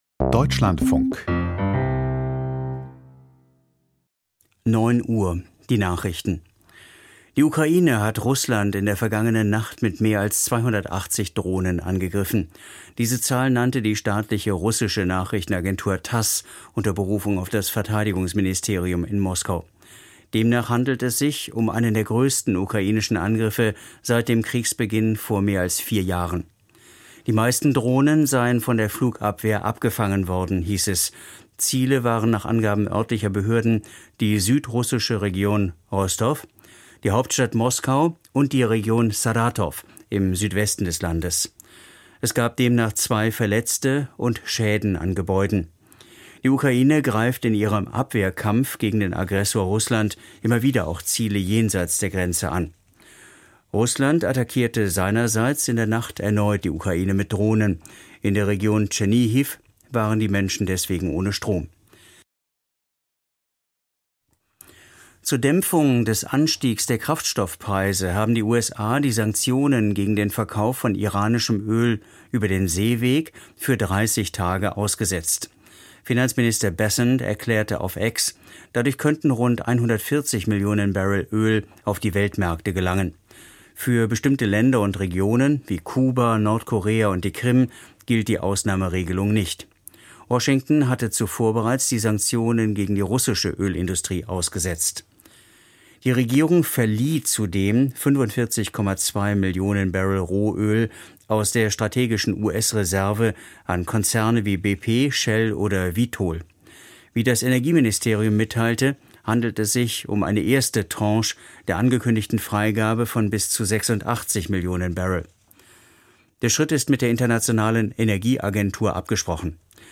Die Nachrichten vom 21.03.2026, 09:00 Uhr